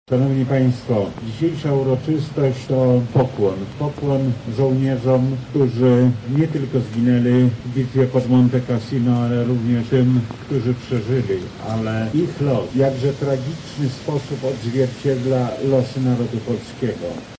-przemawiał wojewoda lubelski, Lech Sprawka.